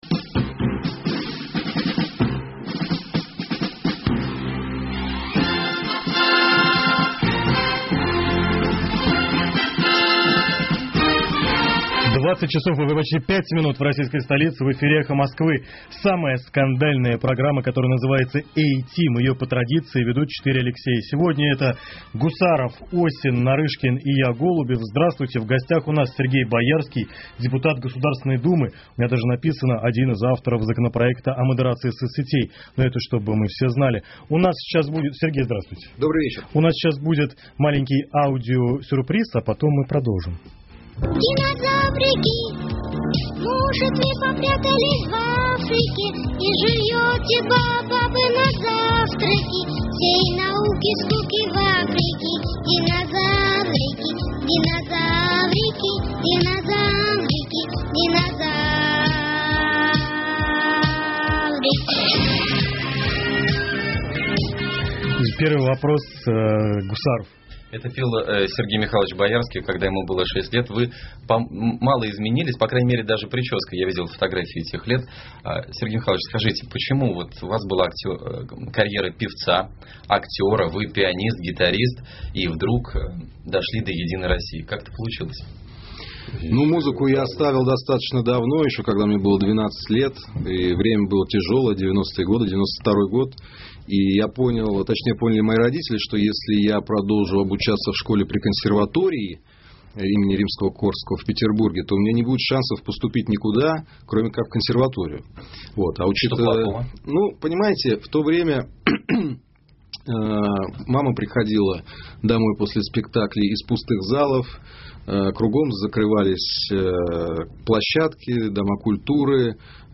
В эфире «Эха Москвы» самая скандальная программа, которая называется A-Team.
В гостях у нас – Сергей Боярский, депутат Государственной думы – у меня даже написано: один из авторов о модерации соцсетей.